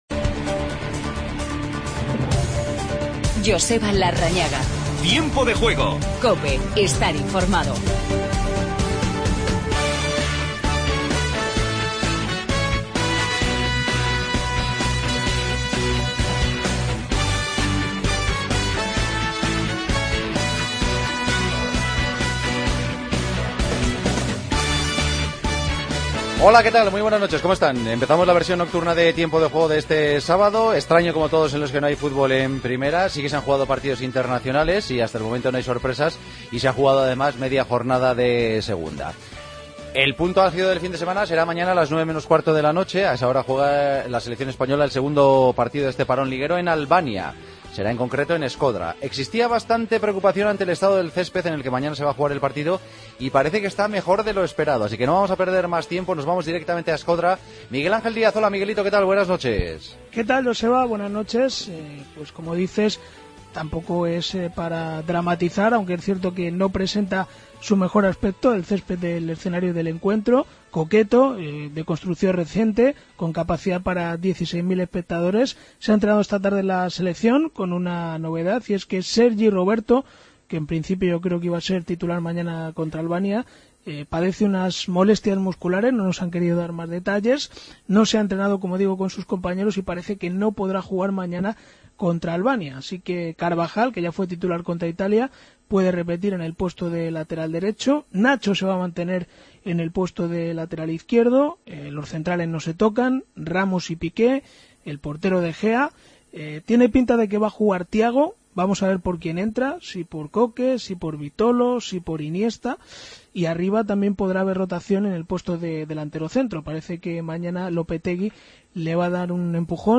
Entrevista a De Biasi.